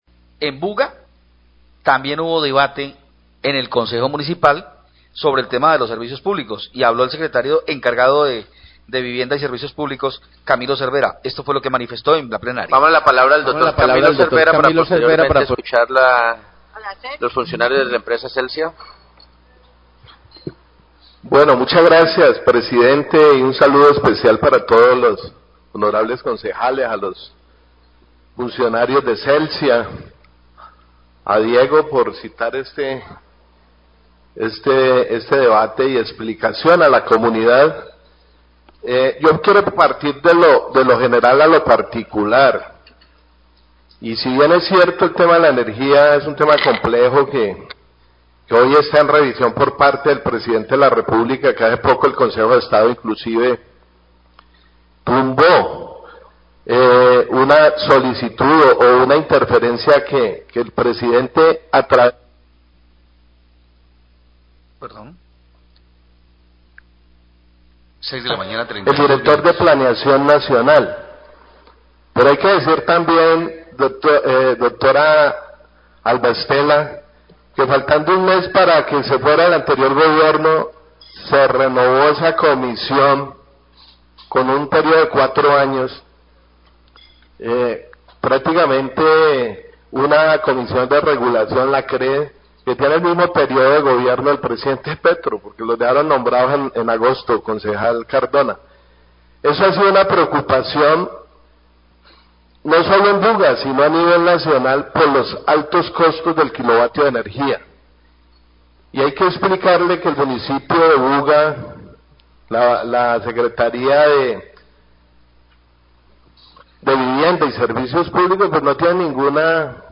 Srio Vivienda y SSPP de Buga habla sobre tarifas de energía durante debate en Concejo
El Secretario (E) de Vivienda y Servicios Públicos de Buga, Camilo Cervera, habla en el debate de control a Celsia por las altas tarifas de energía que realizó ayer el Concejo de Buga y de la formula cómo se establecen las tarifas.